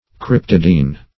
Meaning of cryptidine. cryptidine synonyms, pronunciation, spelling and more from Free Dictionary.
Search Result for " cryptidine" : The Collaborative International Dictionary of English v.0.48: Cryptidine \Cryp"ti*dine\ (kr?p"t?-d?n; 104), n. [Gr. krypto`s hidden.]